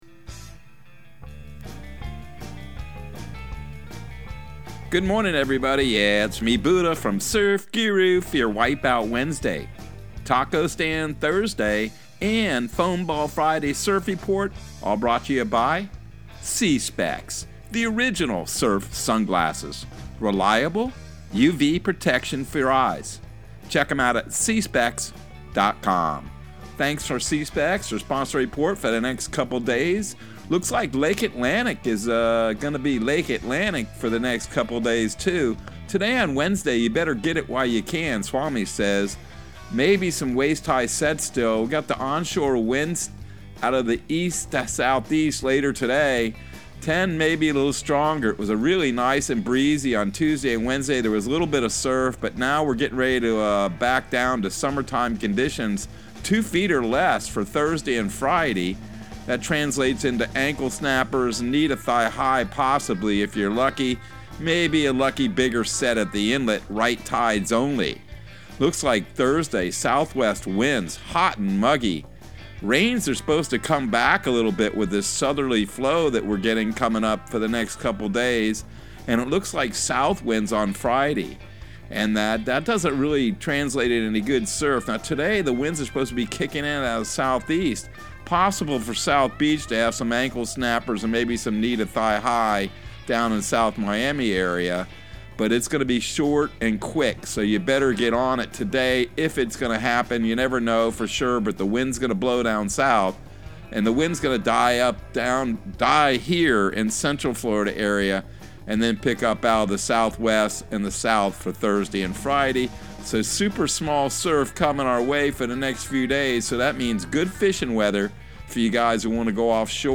Surf Guru Surf Report and Forecast 06/22/2022 Audio surf report and surf forecast on June 22 for Central Florida and the Southeast.